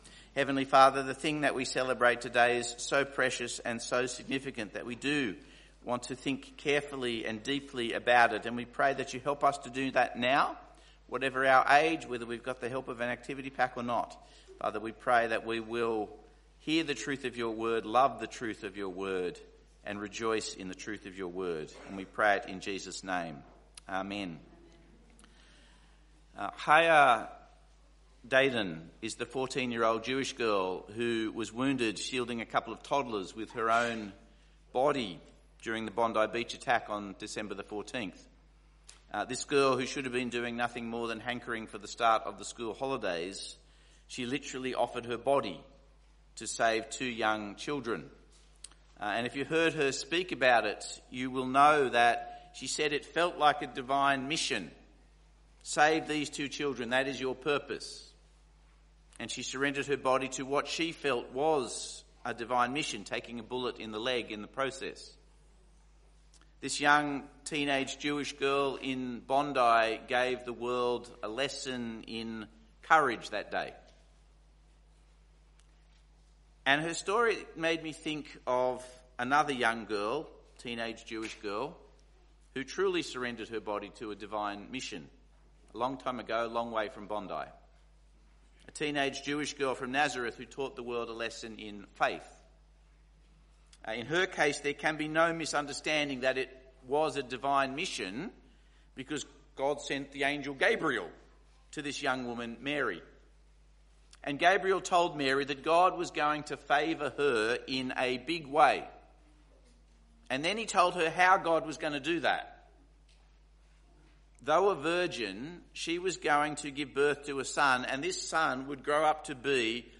Luke | Sermon Books |